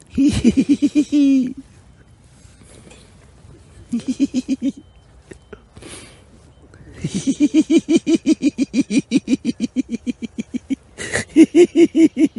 Play śmiech Makłowicza - SoundBoardGuy
smiech-maklowicza.mp3